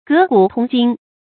格古通今 gé gǔ tōng jīn
格古通今发音